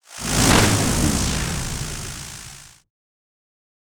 🌲 / foundry13data Data modules soundfxlibrary Combat Single Spell Impact Lightning
spell-impact-lightning-3.mp3